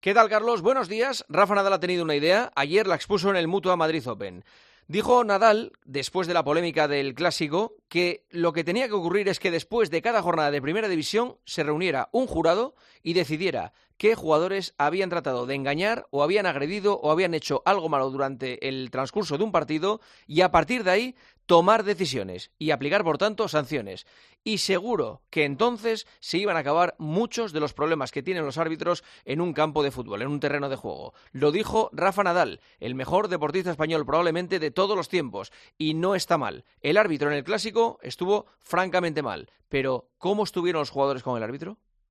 AUDIO: Escucha el comentario del director de 'El Partidazo de COPE', Juanma Castaño, en 'Herrera en COPE'